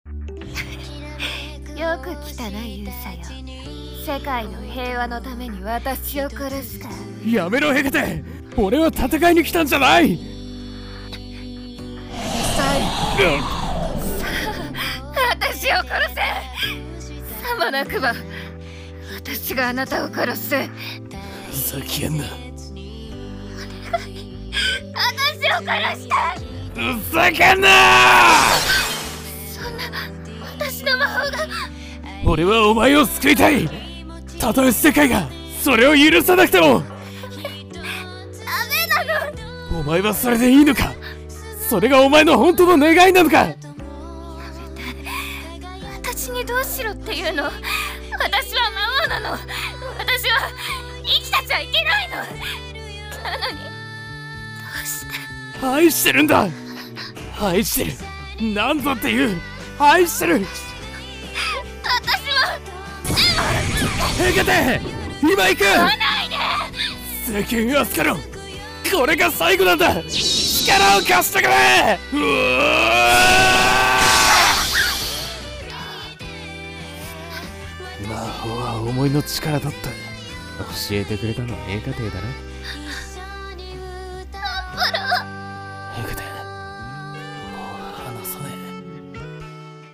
【声劇】勇者を愛した魔王【二人用】